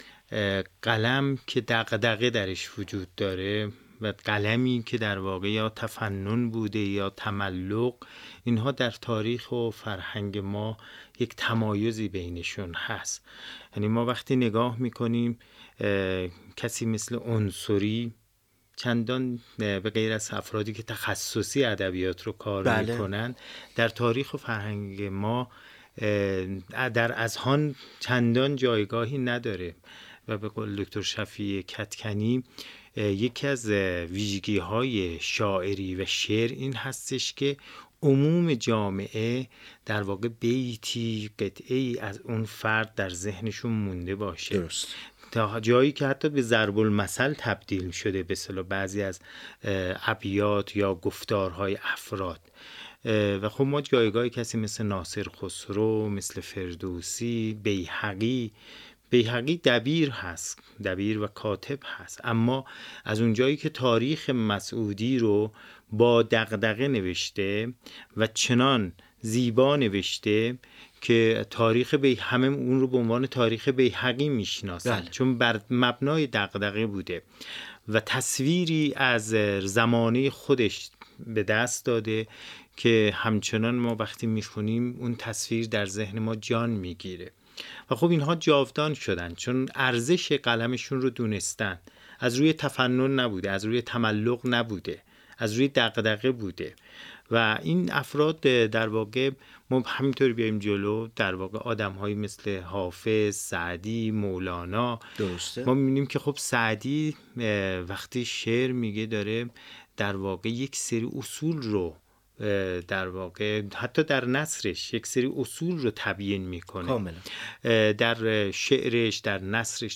بنای این گفت‌وگو روایتی صریح و بی‌پرده بود و در پاره‌ای از فرازهای این گفت‌وگو به‌ سبب زاویه‌هایی که گفتار دو مهمان این میزگرد داشتند، بحث‌های گرمی درگرفت که همراهی با این گفت‌وگو را برای هر مخاطبی جذاب‌تر می‌کند.